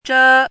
chinese-voice - 汉字语音库
zhe1.mp3